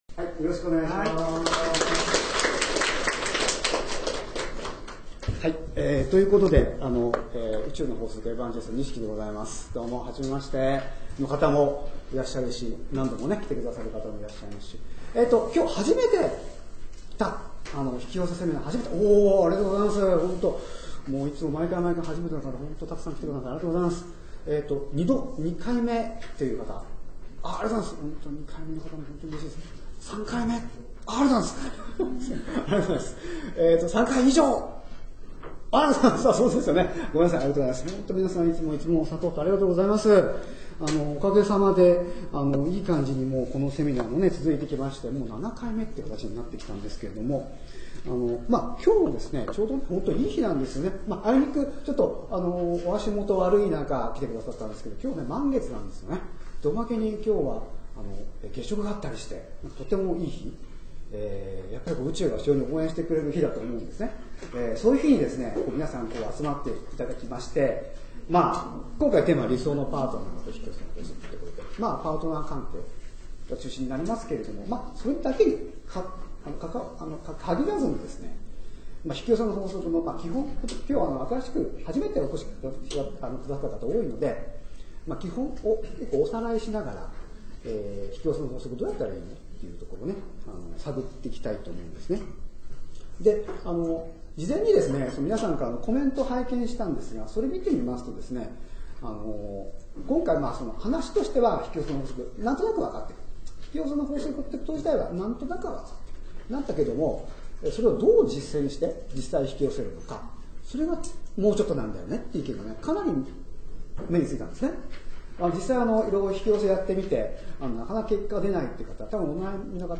大阪セミナー　　２０１０年７月末販売開始
瞑想的なワークショップも全員で行いました。
合計収録時間　１５４分５２秒　エル大阪にて収録